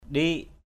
/ɗi:ʔ/